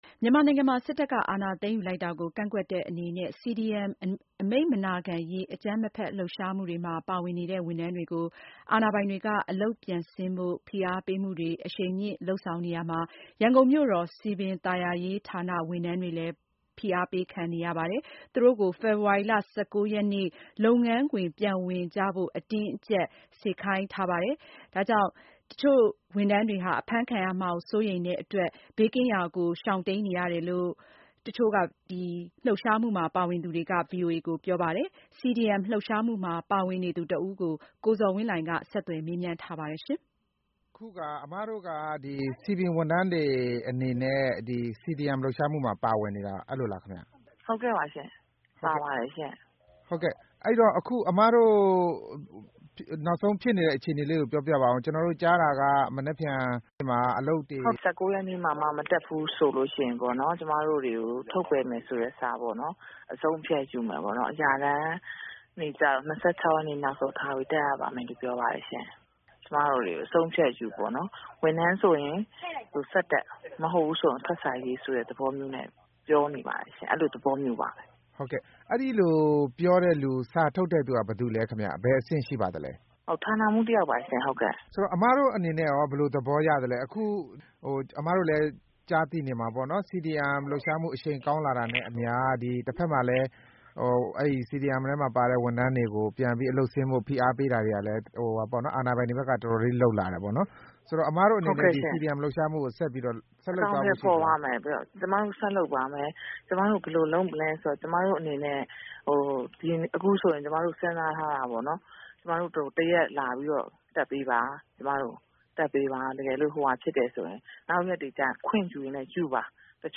အလုပ်ဆင်းဖို့ ဖိအားပေးခံနေရတဲ့ စည်ပင်ဝန်ထမ်းတဦးနဲ့ ဆက်သွယ်မေးမြန်းချက်